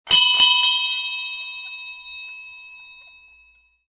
Колокольчик речного парохода